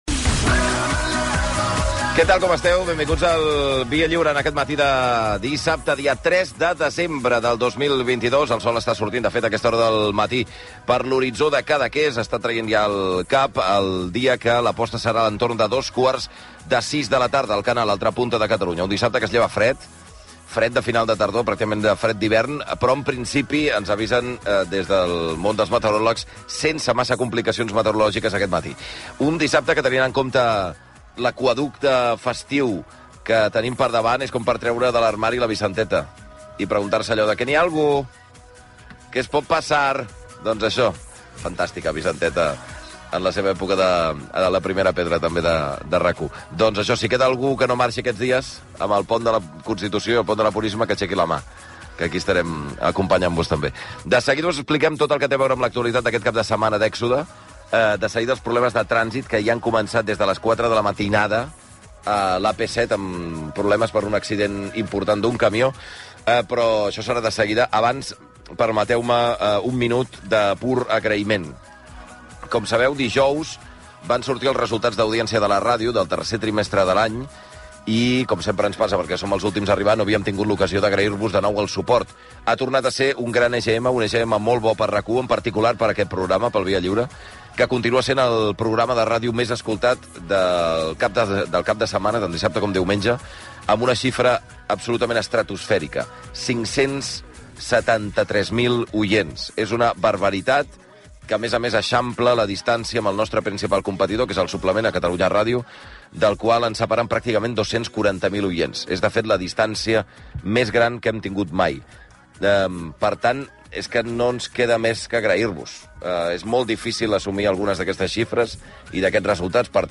Entreteniment
FM